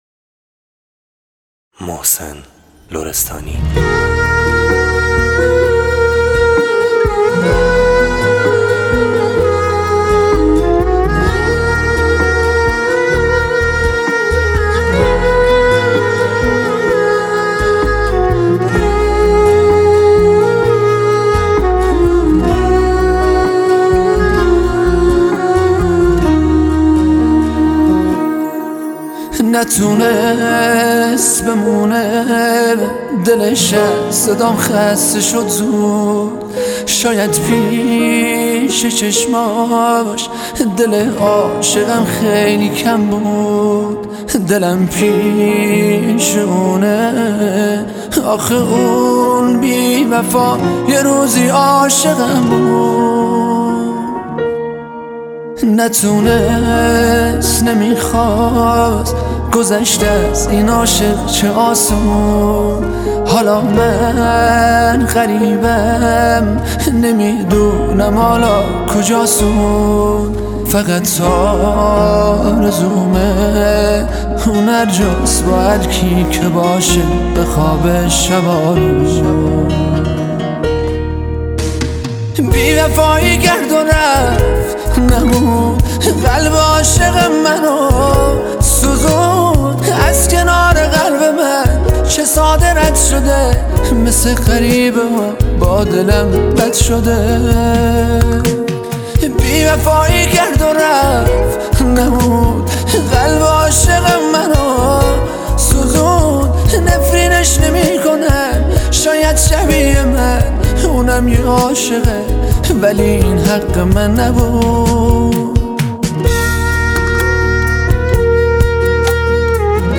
این آهنگ عاشقانه و غمگین